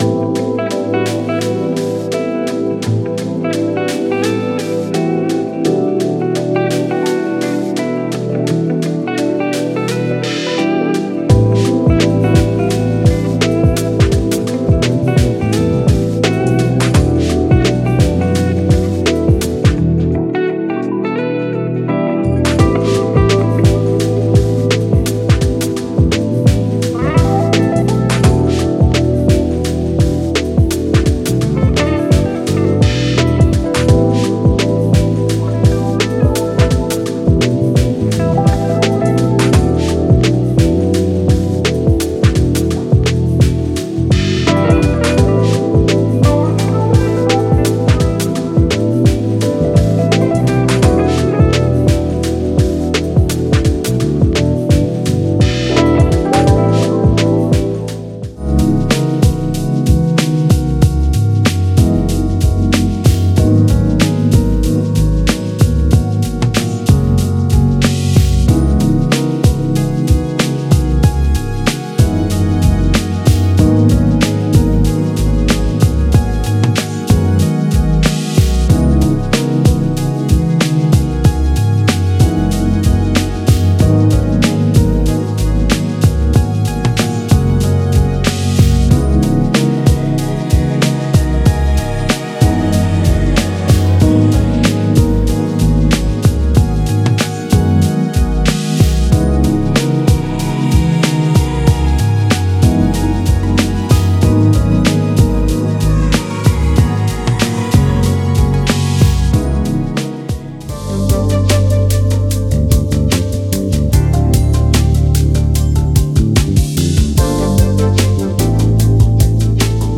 Genre:Soul
グルーヴの黄金時代に立ち返り、深みのあるサウンドを追求しました。
すべての要素は24ビット音源でプロフェッショナルに録音されており、クリーンで高品質なサウンドを提供します。
デモサウンドはコチラ↓